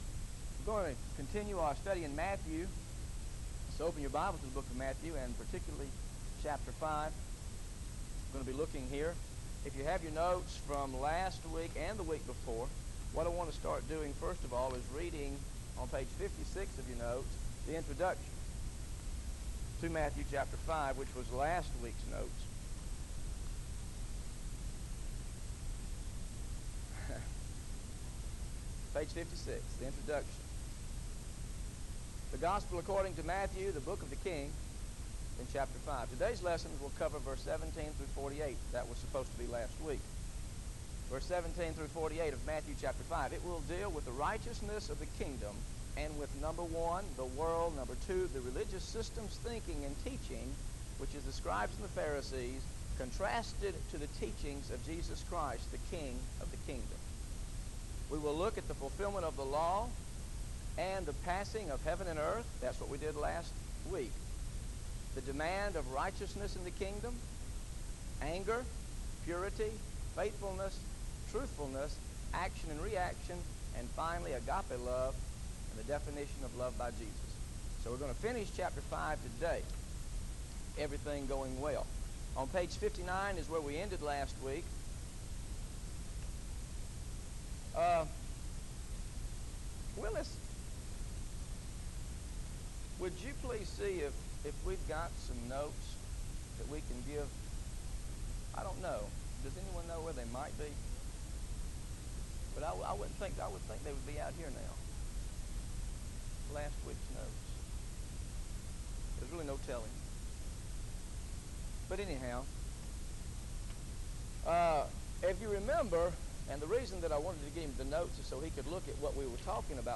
Listen to the Teaching on Matthew 5 Part 3 Audio